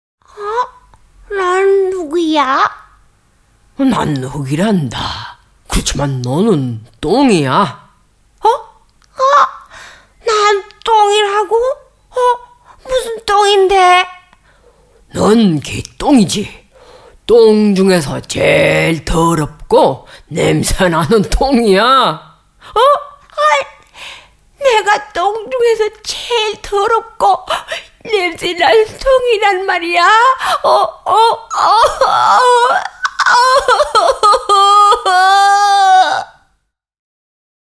• junge, erfrischende und freundliche Stimme • besonders im Bereich Musik, Lifestyle einsetzbar • umfangreiches Klang- & Volumenspektrum • wandelbar bzw. vielseitig, süß, sanft, dynamisch, warm, sexy, lustig
Sprechprobe: Sonstiges (Muttersprache):